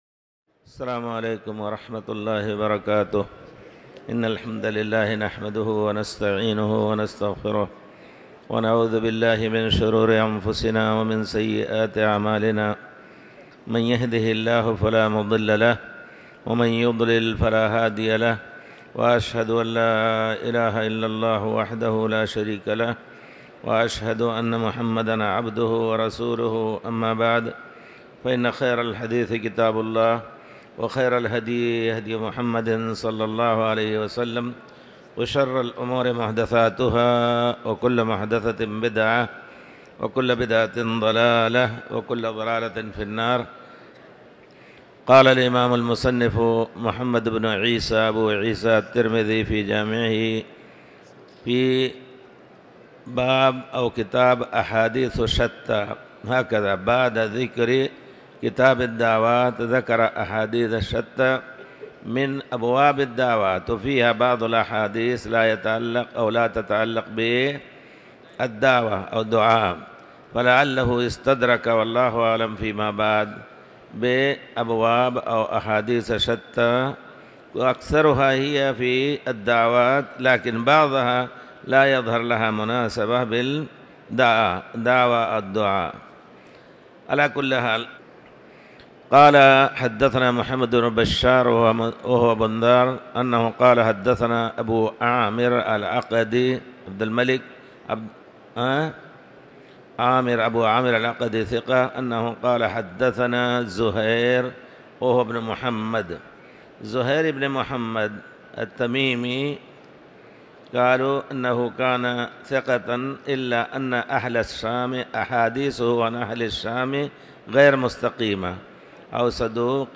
تاريخ النشر ١٨ جمادى الآخرة ١٤٤٠ هـ المكان: المسجد الحرام الشيخ